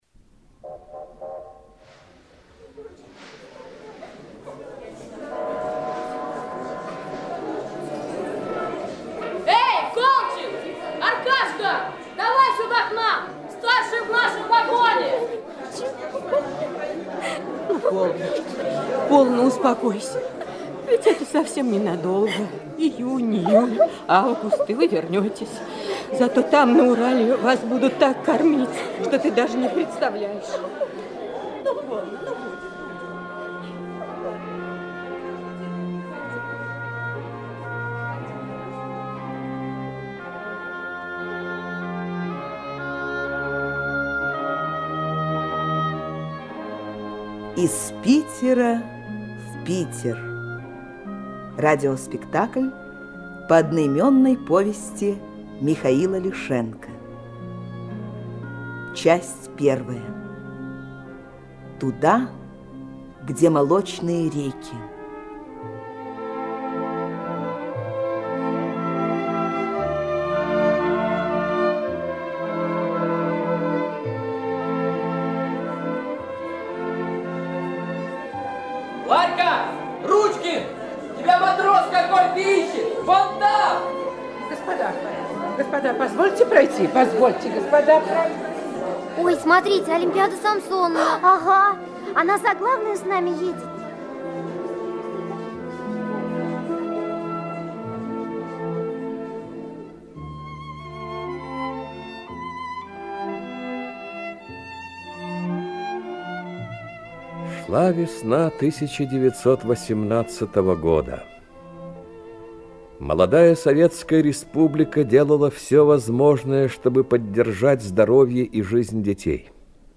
В эпизодах и массовых сценах артисты московских театров и школьники.
радиопостановка, спектакль, проза